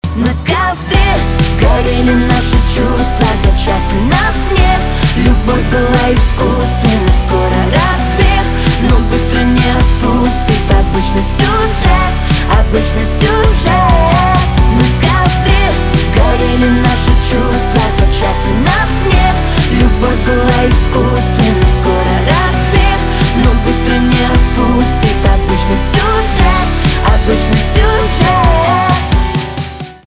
поп грустные гитара
мужской голос женский голос
пианино барабаны